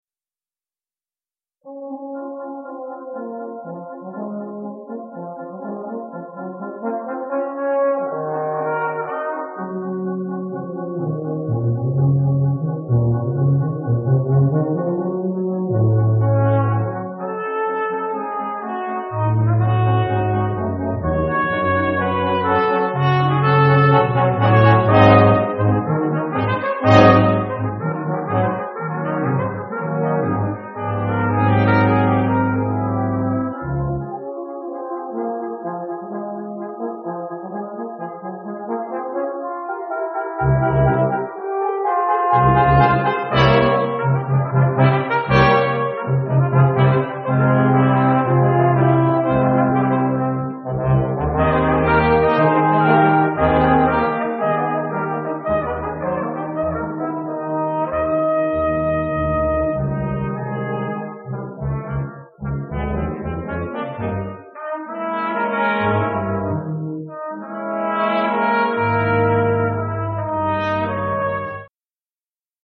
Polished Brass
Westwood Presbyterian Church